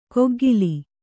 ♪ koggili